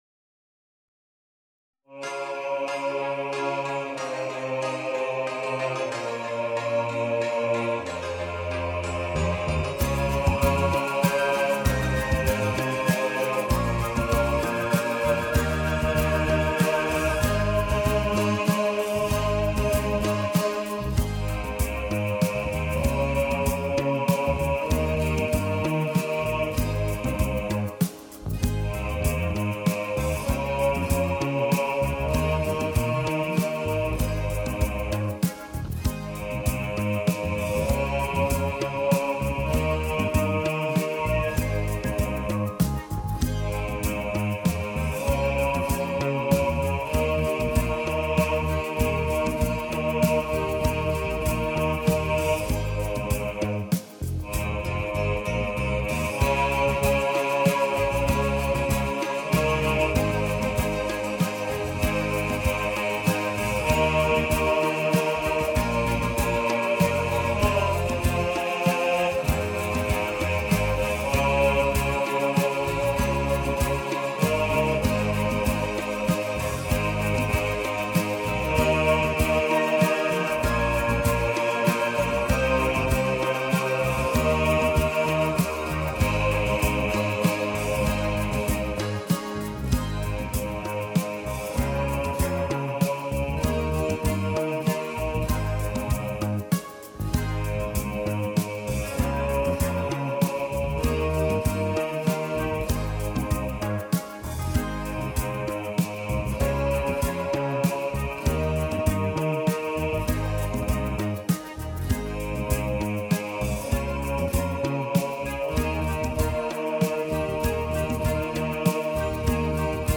Move On Bass | Ipswich Hospital Community Choir
Move-On-Bass.mp3